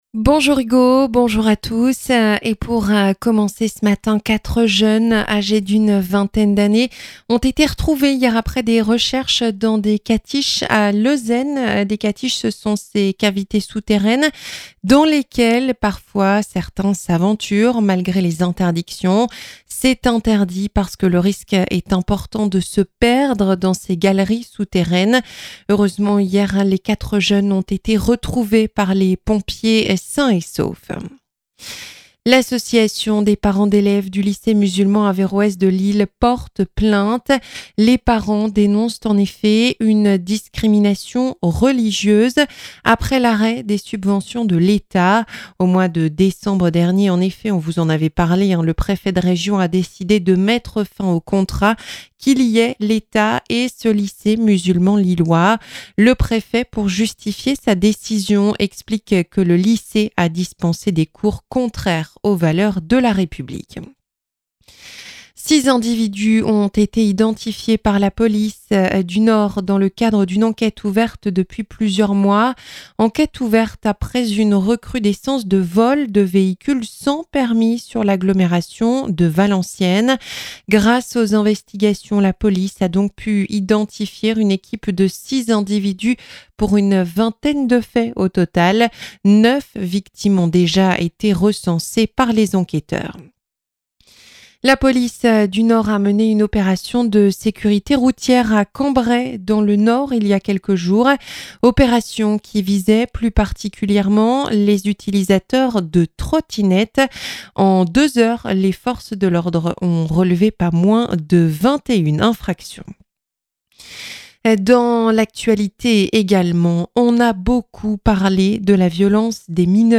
Journal 9h - 4 jeunes gens retrouvés dans les catiches à Lezennes hier